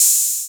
OH808D6.wav